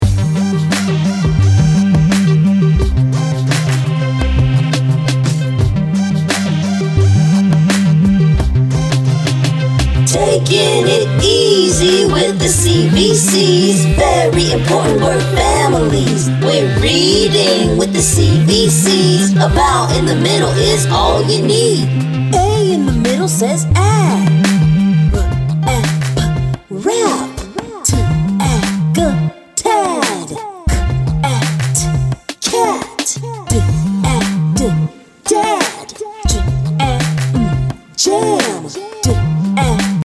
Demo MP3